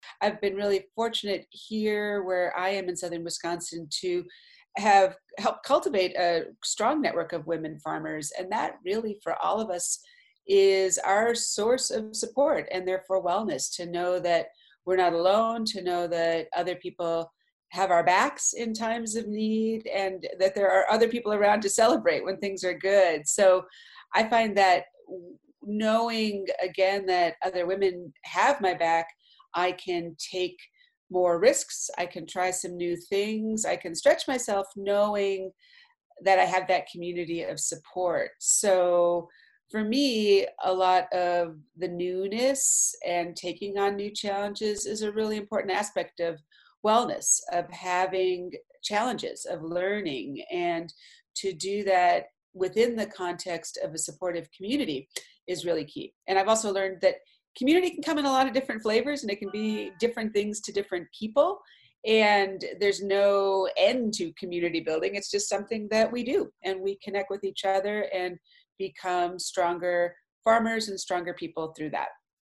Interview With The Soil Sisters - Farm Well Wisconsin